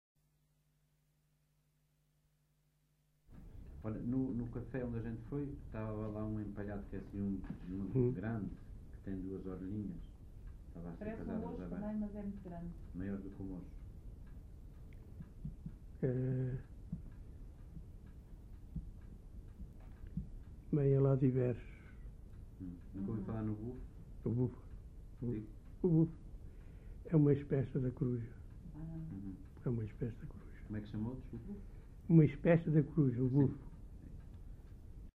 LocalidadeFigueiró da Serra (Celorico da Beira, Guarda)